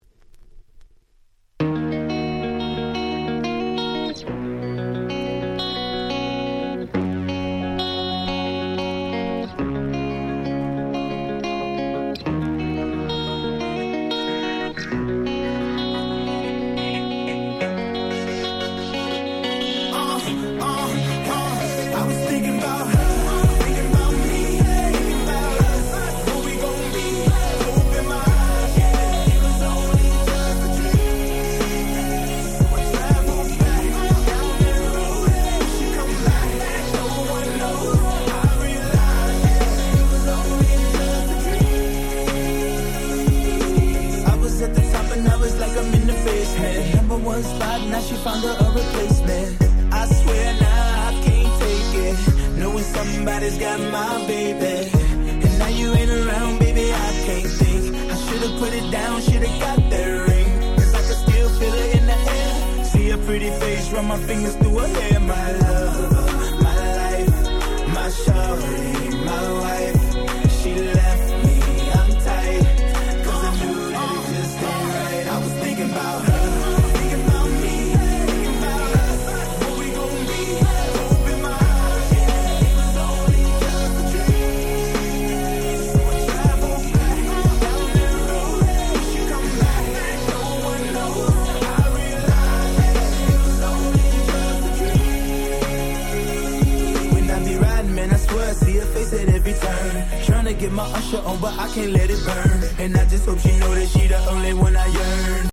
10' Super Hit Hip Hop !!
希望に満ち溢れた感満載のキャッチーなサビが堪りません！！